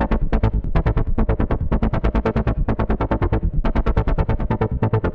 Index of /musicradar/dystopian-drone-samples/Droney Arps/140bpm
DD_DroneyArp1_140-A.wav